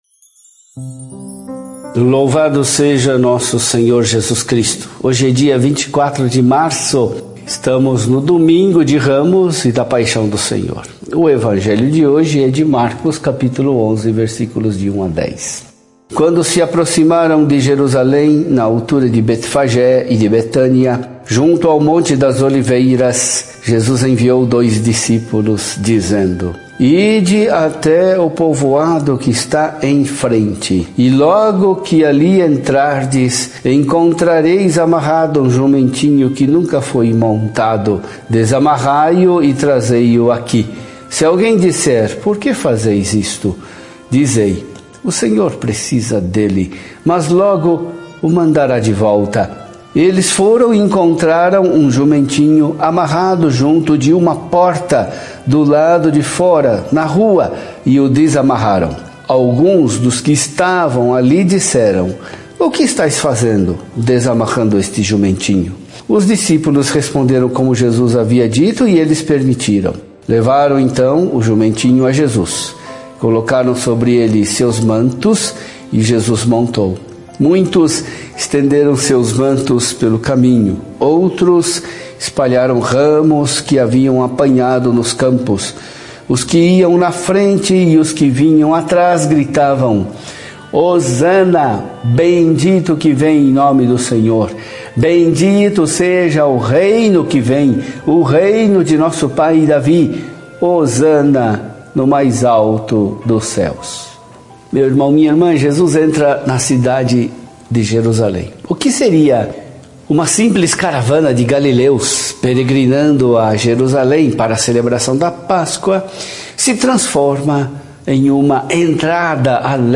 Por Dom Julio Endi Akamine SAC